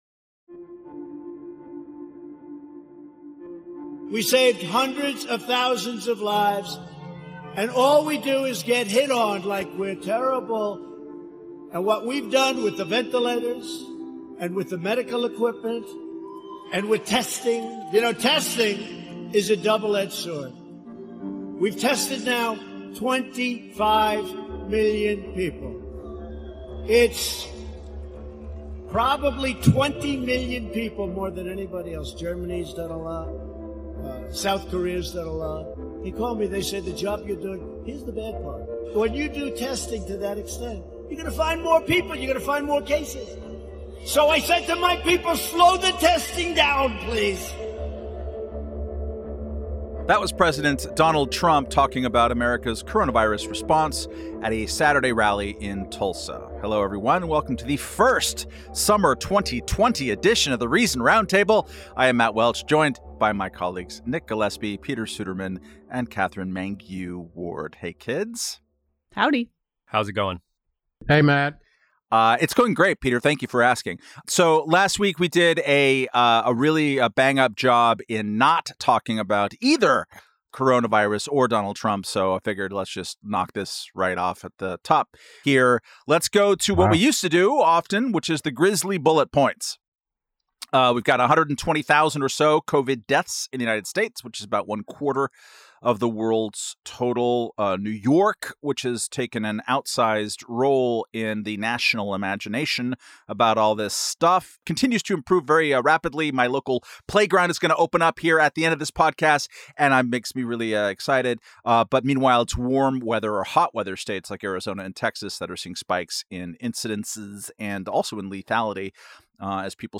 From Forrest to Roosevelt, Confederates to Cervantes, Washington to Whittier, a discussion of iconography politics on The Reason Roundtable.